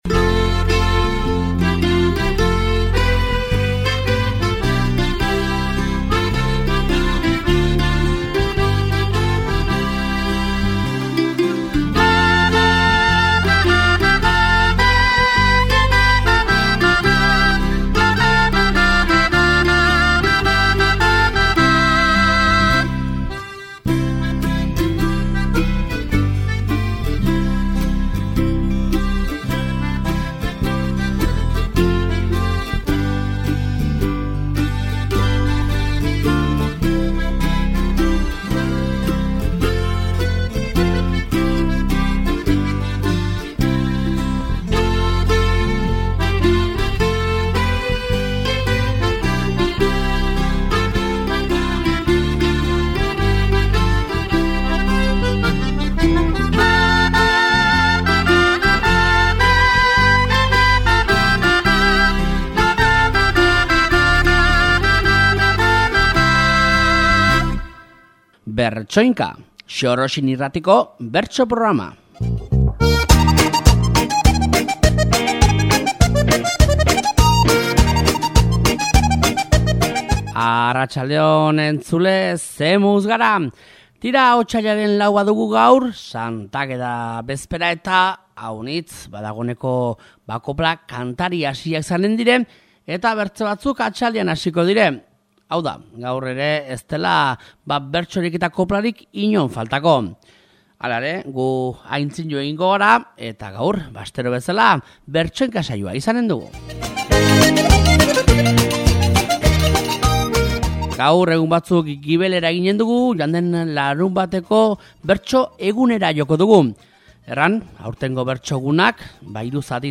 Joan den larunbateko Bertso eguneko ekitaldi nagusiko bertsoak, aste honetako Bertsoinka saioan